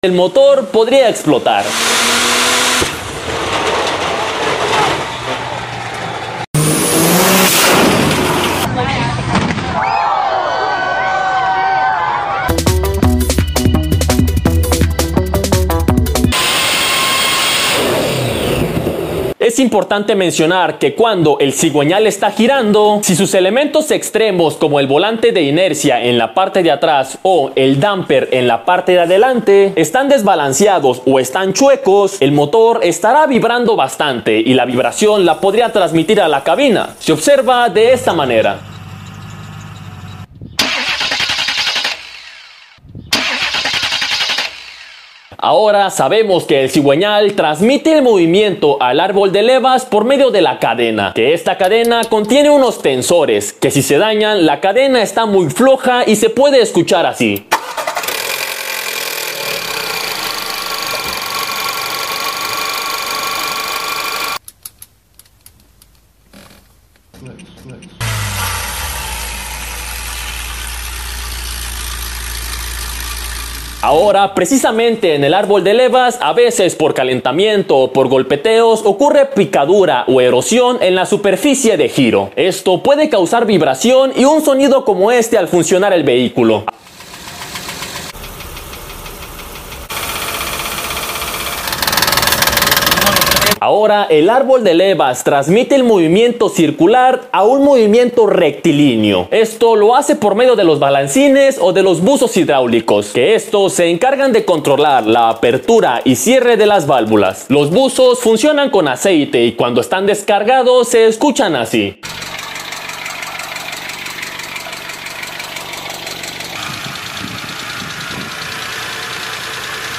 TODOS LOS RUIDOS del MOTOR, sound effects free download
TODOS LOS RUIDOS del MOTOR, CLUTCH, TRANSMISIÓN y DIFERENCIAL VIBRACIÓN, SONIDOS al ACELERAR CARRO